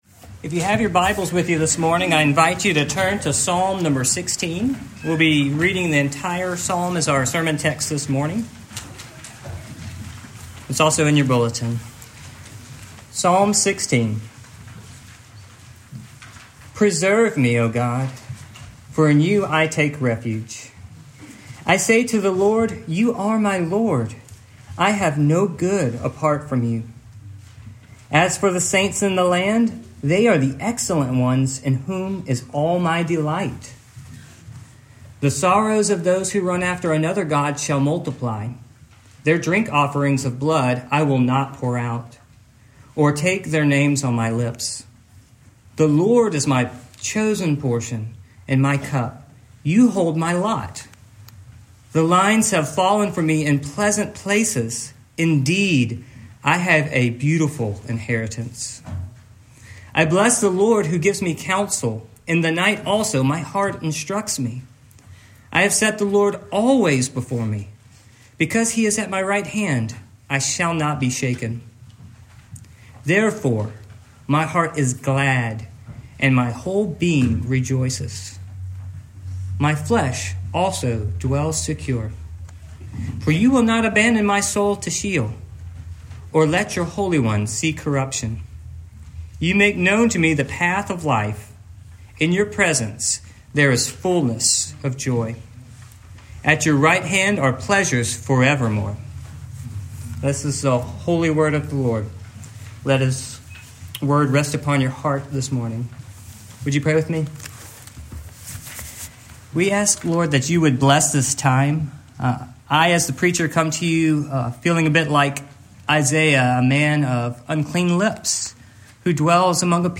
Psalm 16 Service Type: Morning Main Idea